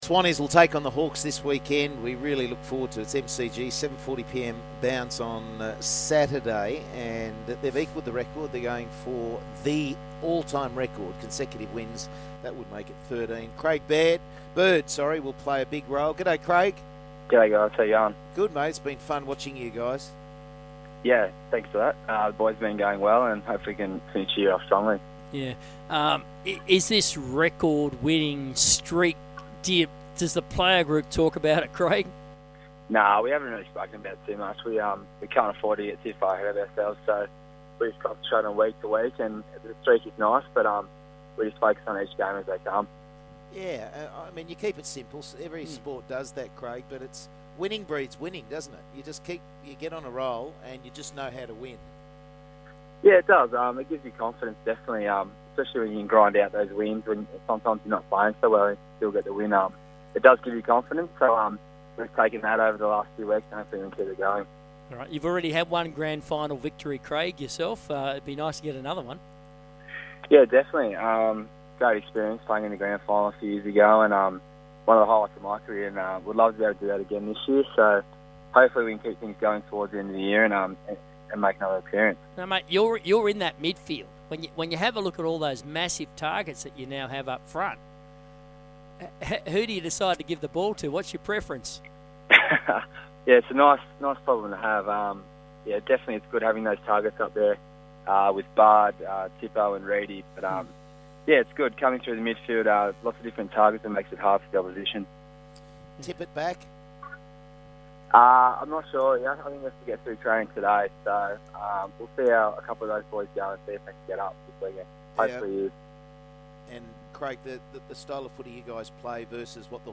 Sydney Swans midfielder Craig Bird appeared on Sky Sports Radio's Big Sport Breakfast program on Thursday July 24, 2014